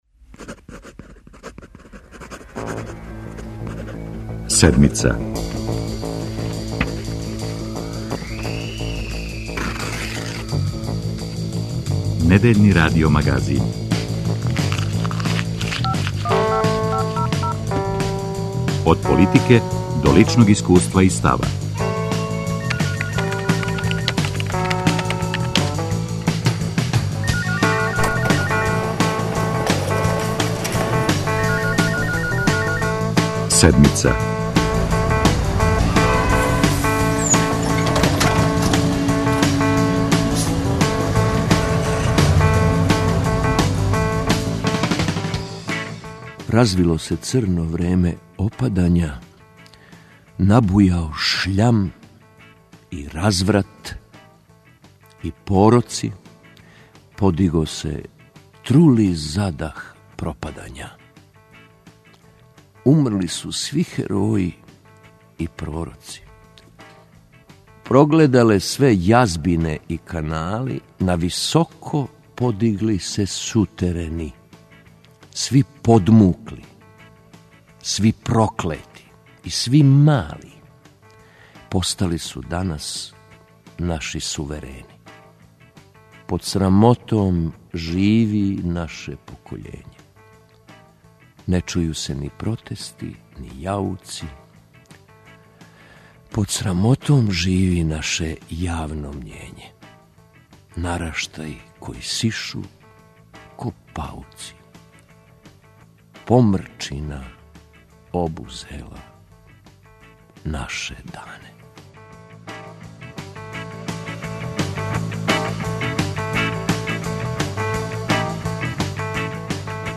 Гост Седмице је Радослав Рале Миленковић.